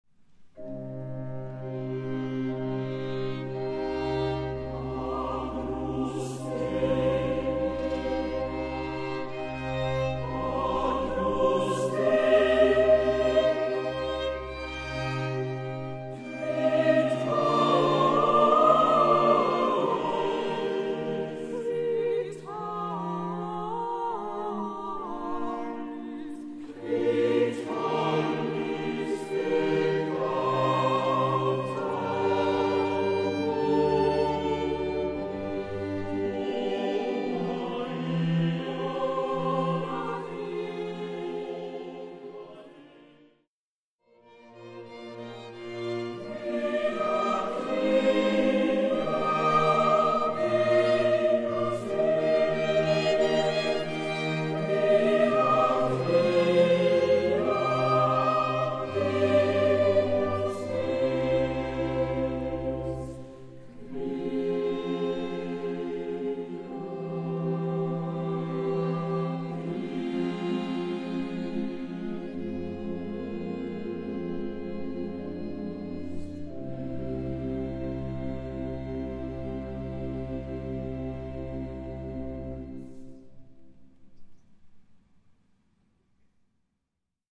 — Ausschnitte aus dem Konzert der Kaufbeurer Martinsfinken in Irsee vom 21.3.10: